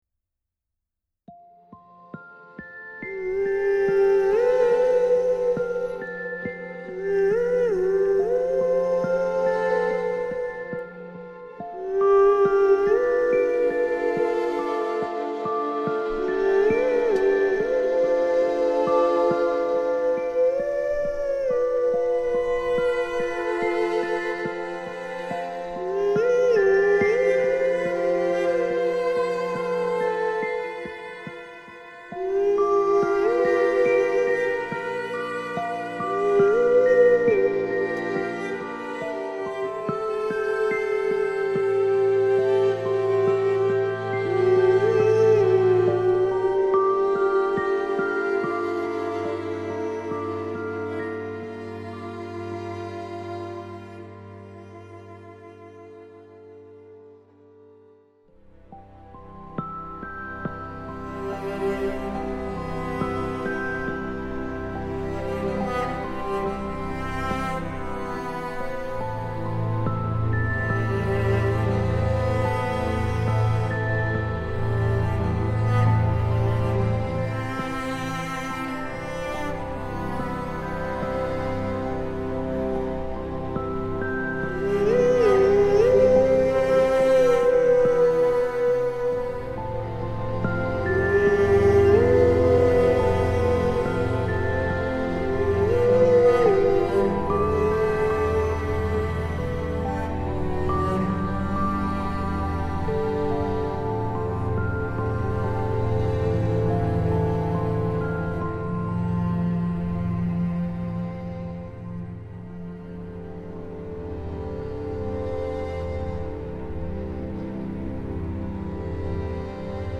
girl voice melody